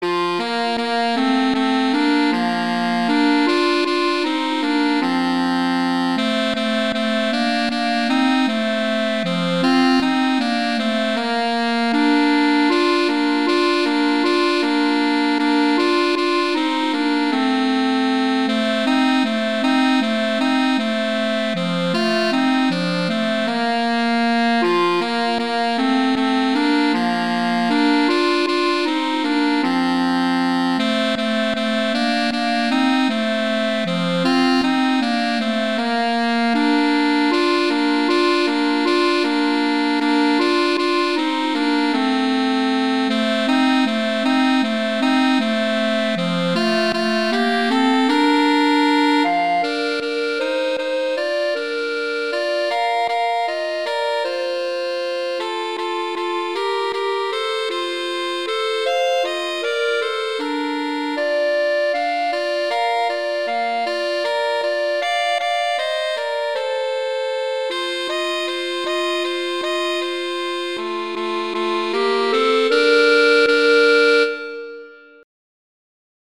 winter, holiday, hanukkah, hymn, sacred, children